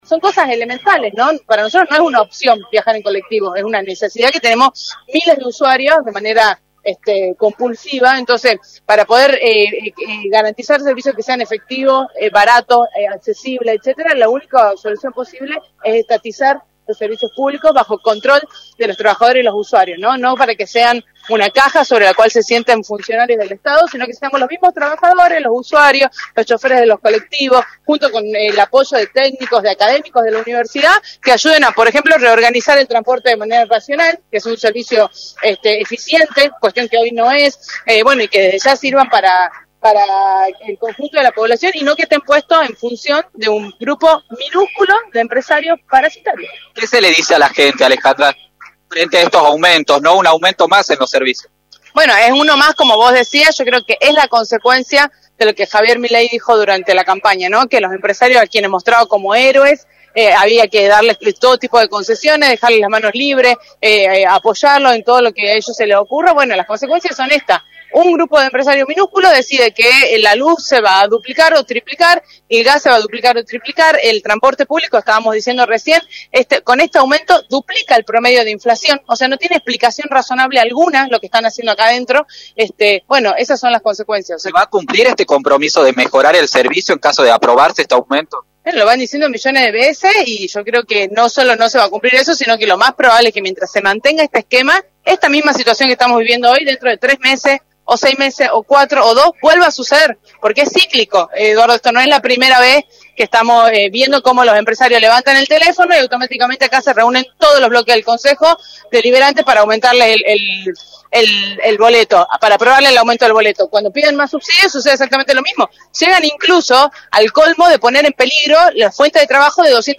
en entrevista para Radio del Plata Tucumán, por la 93.9.